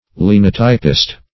-- Lin"o*typ`ist, n.